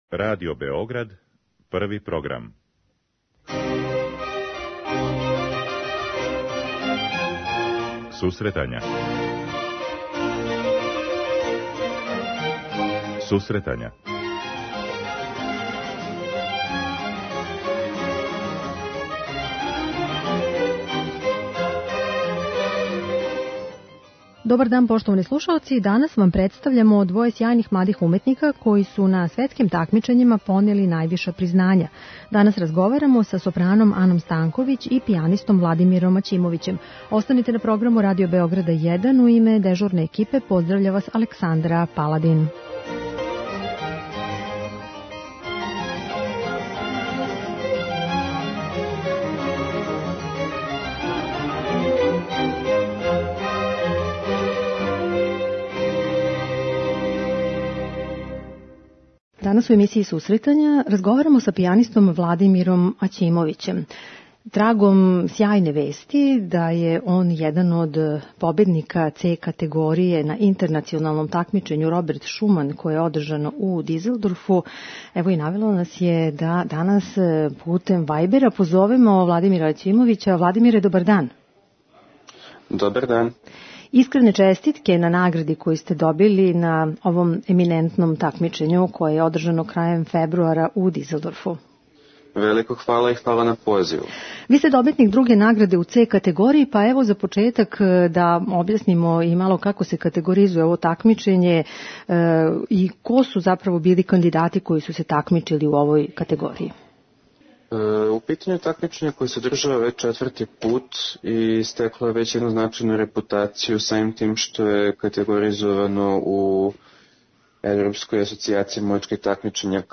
Интернационалном такмичењу "Grande voci" у Салцбургу, у Аустрији. преузми : 9.35 MB Сусретања Autor: Музичка редакција Емисија за оне који воле уметничку музику.